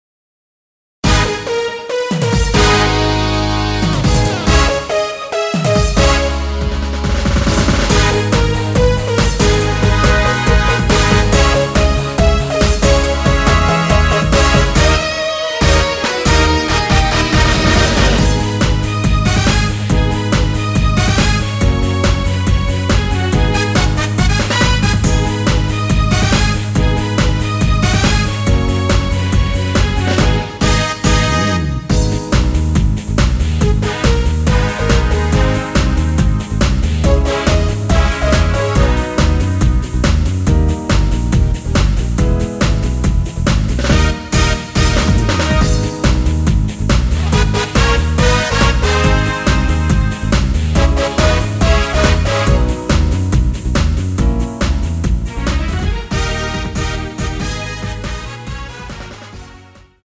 MR입니다.
Abm
앞부분30초, 뒷부분30초씩 편집해서 올려 드리고 있습니다.
중간에 음이 끈어지고 다시 나오는 이유는